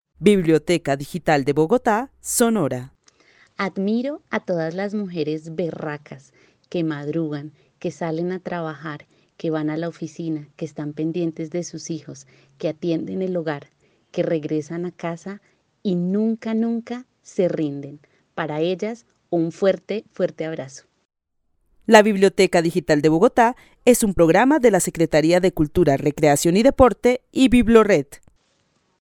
Narración oral de una mujer que vive en la ciudad de Bogotá y admira a todas las mujeres berracas que salen a trabajar, que van a la oficina, que están pendientes de sus hijos, que atienden el hogar, que regresan a casa y nunca se rinden. El testimonio fue recolectado en el marco del laboratorio de co-creación "Postales sonoras: mujeres escuchando mujeres" de la línea Cultura Digital e Innovación de la Red Distrital de Bibliotecas Públicas de Bogotá - BibloRed.